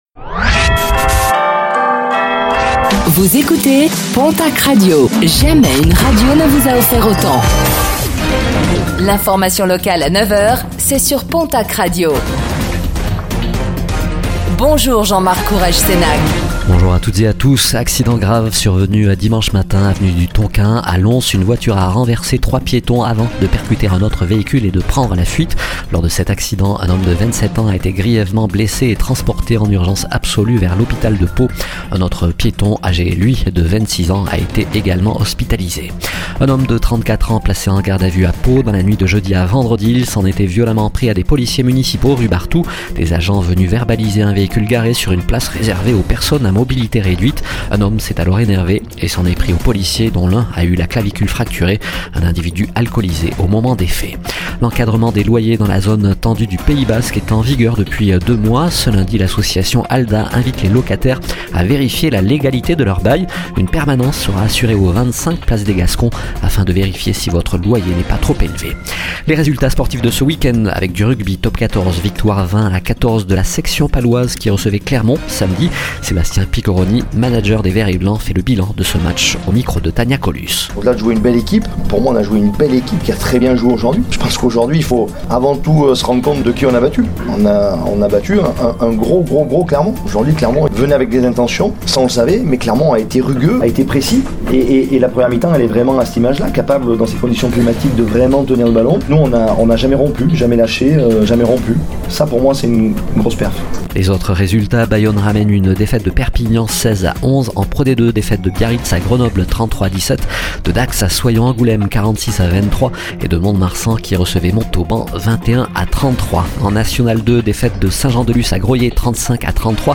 Réécoutez le flash d'information locale de ce lundi 27 janvier 2025